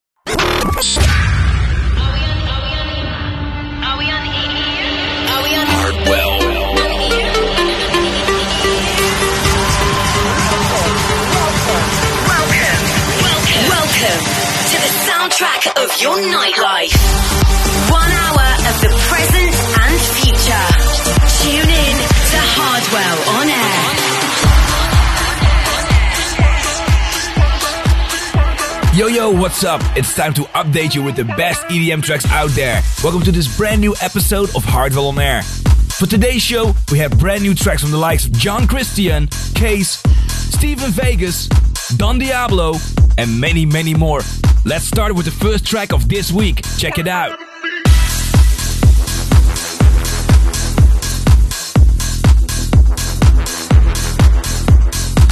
latest floorfillers